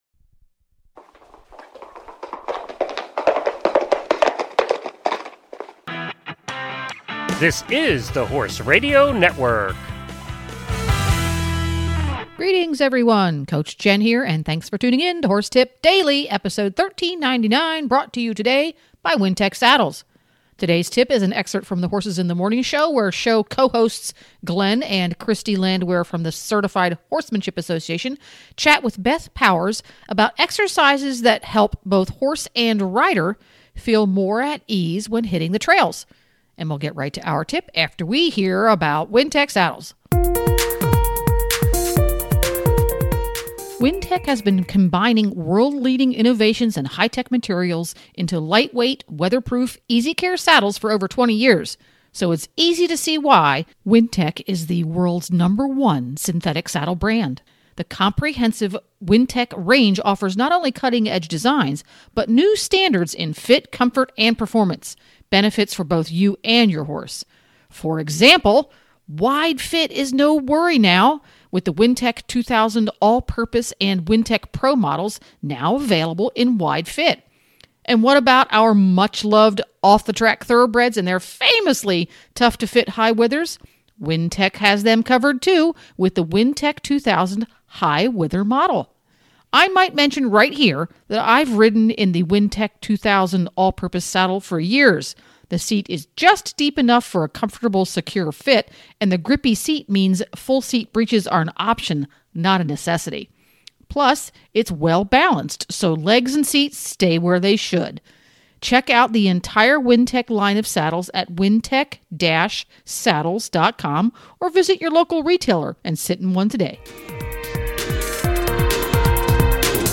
Today's tip is an excerpt from the HITM show where show co-hosts